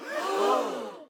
sfx_gasp.ogg